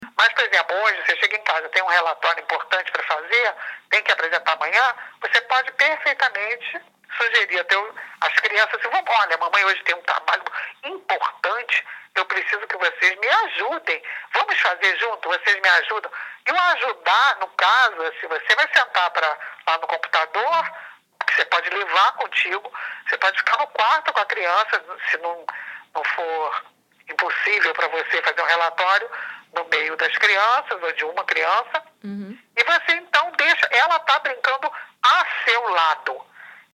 Entrevista especial com Tania Zagury- FOTO TANIA